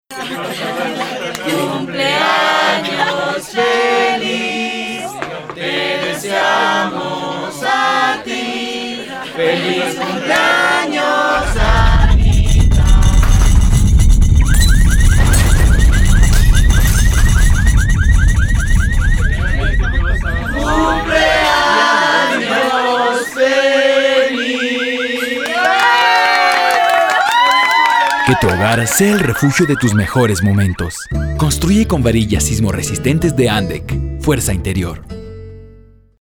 Cuña de radio para el concepto "Fuerza Interior" de la marca Acería Nacionales del Ecuador (ANDEC) y su producto Acero Sismo Resistente.